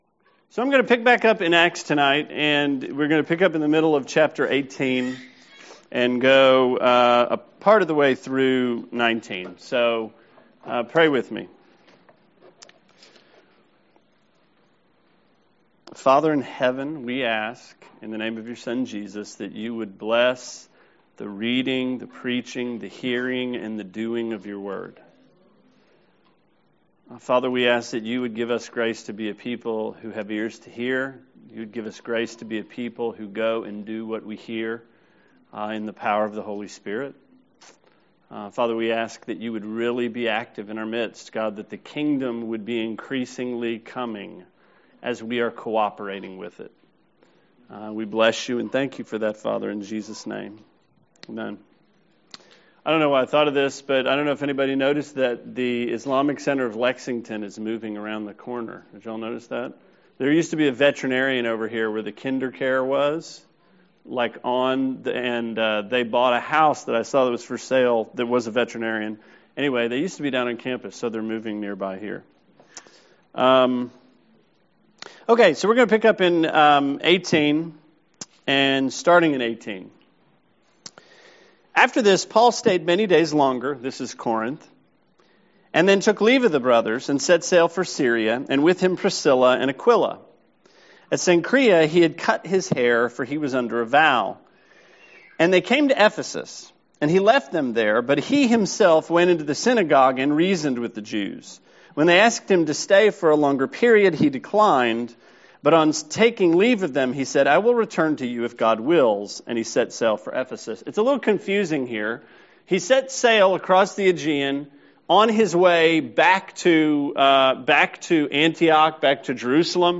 Sermon 8/5: Acts 18:19-28